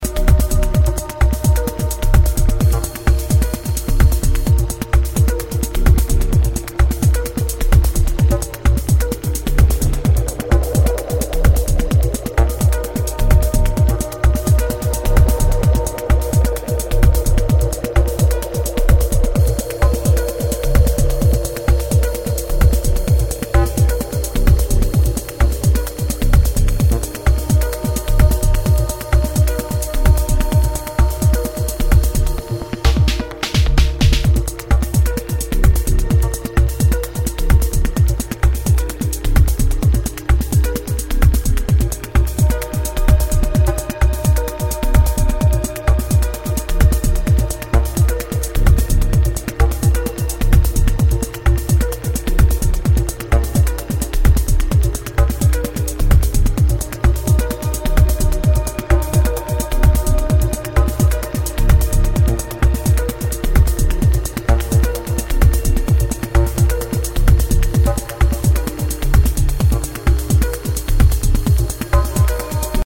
ヒプノティックなアシッドリフがクールな熱気を孕んだブロークンビーツ